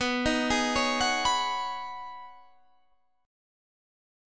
Listen to B6add9 strummed